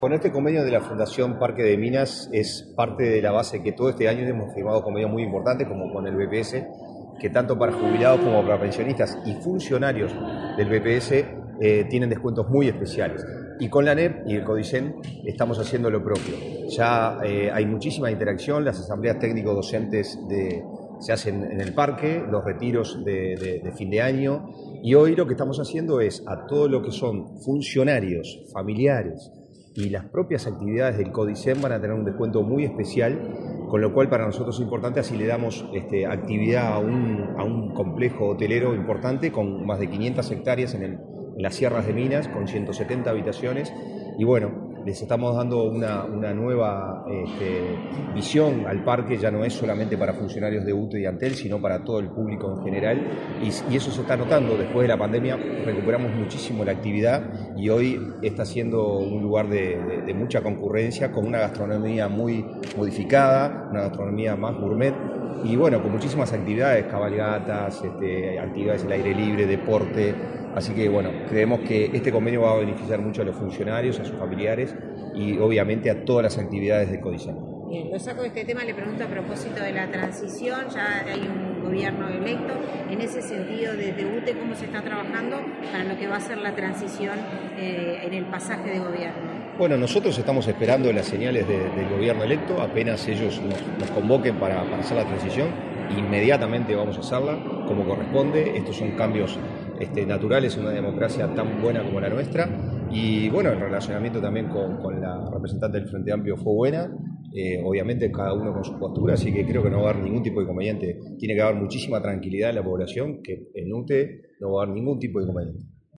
El vicepresidente de la UTE y presidente del Parque de Minas, Pablo Ferrari, dialogó con la prensa, antes de firmar un convenio con la titular de la Administración Nacional de Educación Pública (ANEP), Virginia Cáceres. El acuerdo permite otorgar descuentos en las tarifas de los servicios de hotelería, descanso y recreación en ese establecimiento vacacional a los funcionarios de la ANEP.